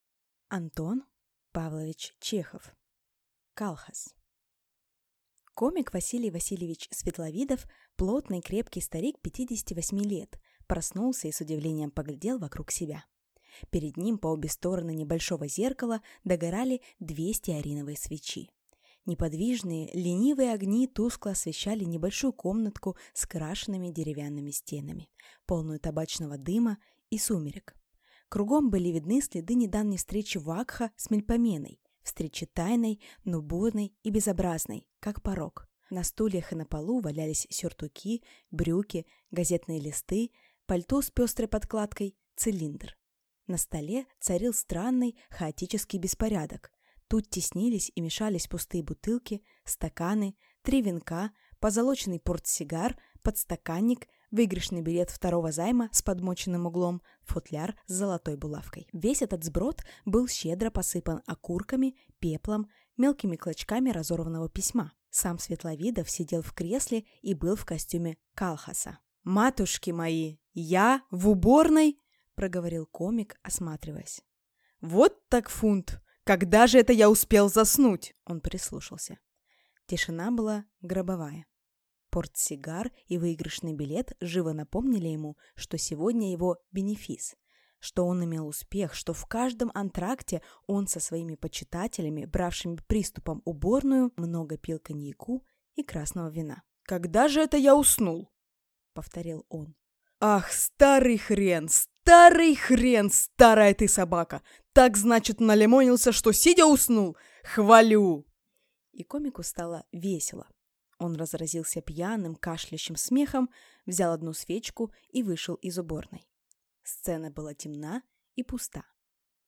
Аудиокнига Калхас | Библиотека аудиокниг